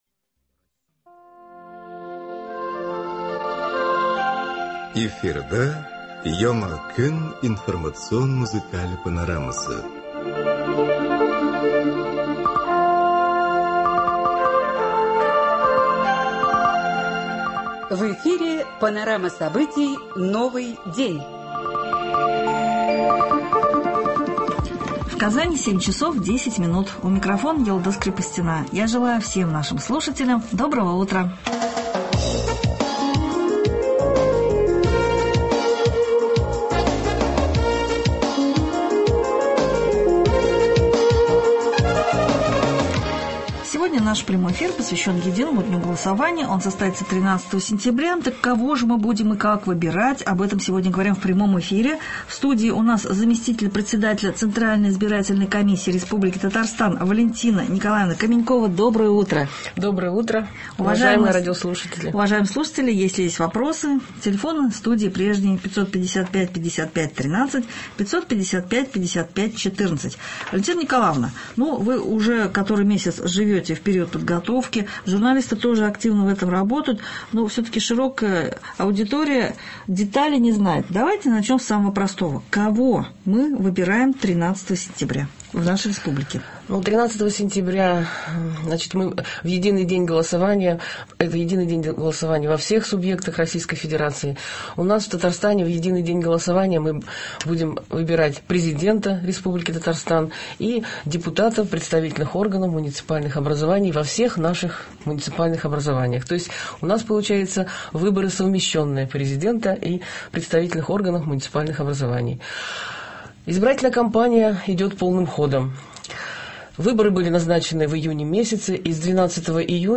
Заместитель Председателя Центральной избирательной комиссии Республики Татарстан В.Н.Каменькова выступила в прямом радиоэфире на Радио Татарстана.